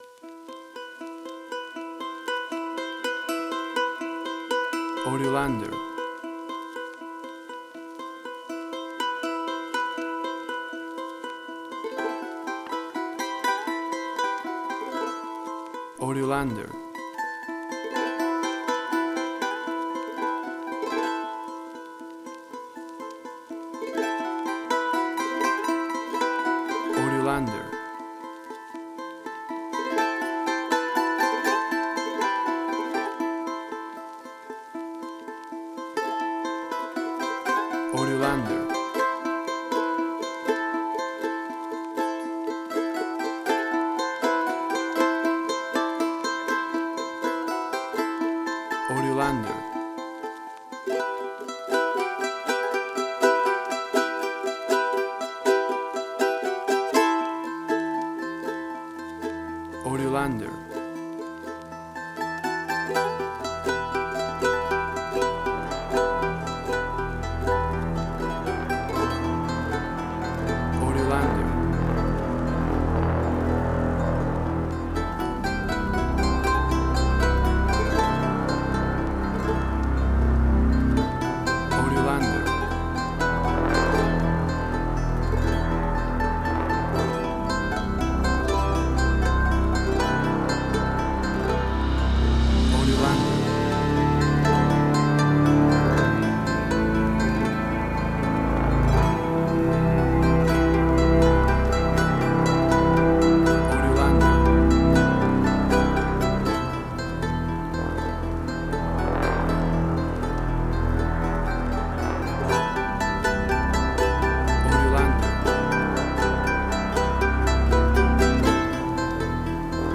Latin Drama_Similar_Narcos.
WAV Sample Rate: 16-Bit stereo, 44.1 kHz
Tempo (BPM): 80